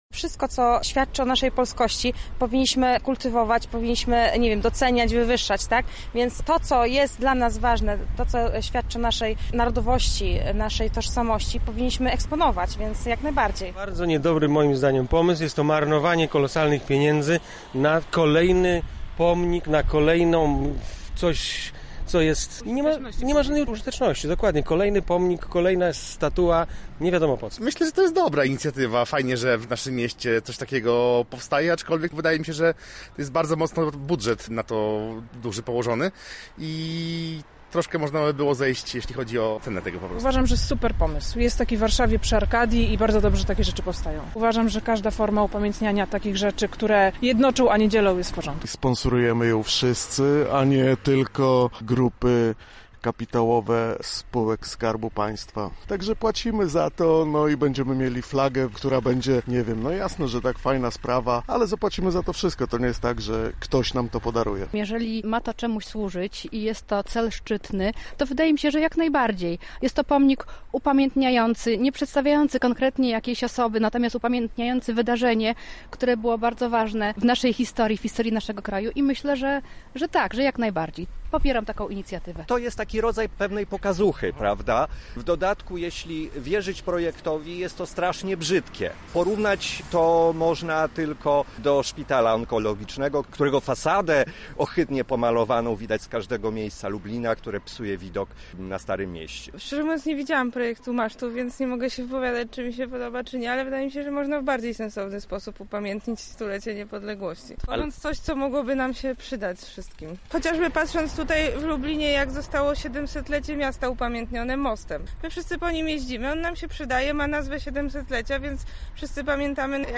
Sonda
maszt-niepodległośći-sonda-CU.mp3